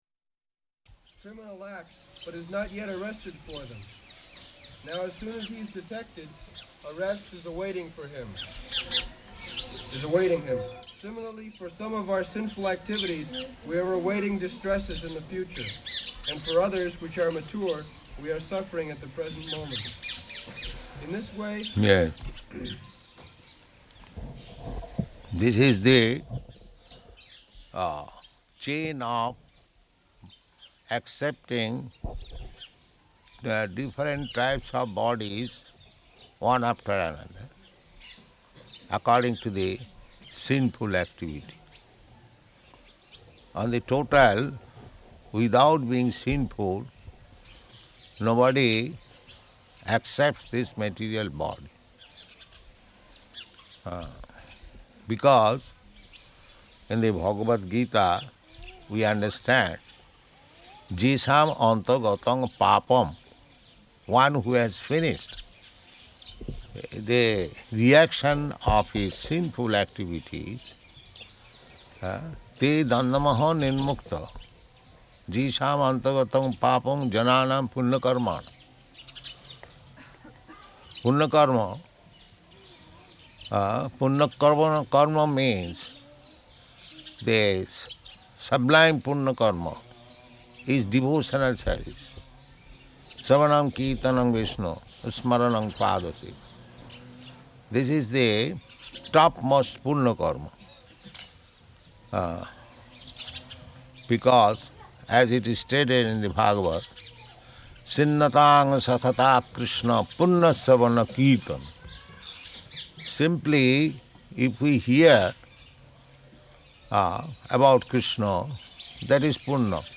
November 5th 1972 Location: Vṛndāvana Audio file